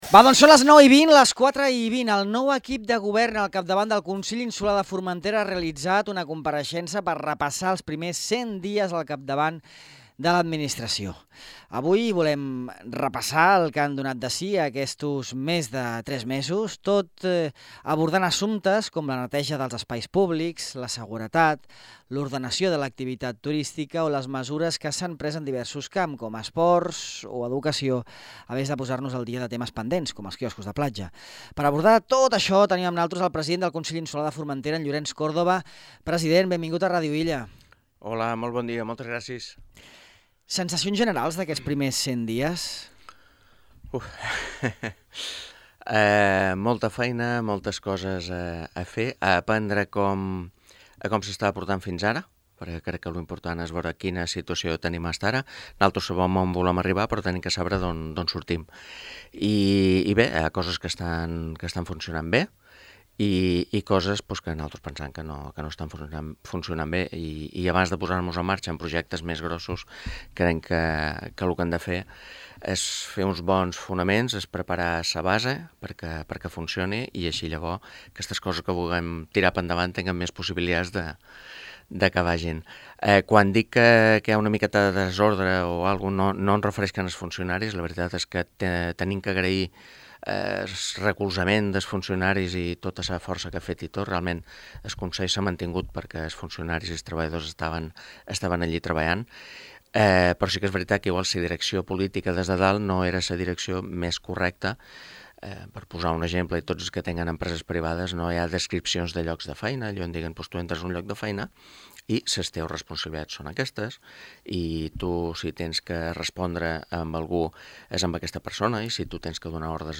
Llorenç Córdoba, president del Consell de Formentera, ha repassat en aquesta entrevista a Ràdio Illa sobre els primers 100 dies de Govern la darrera hora del concurs de quioscos de platja, de les investigacions de la Fiscalia, de les negociacions amb la nova concessionària de fems, del canvi al capdavant de la conselleria d’Esports o de les accions pendents en aquesta àrea, entre altres qüestions.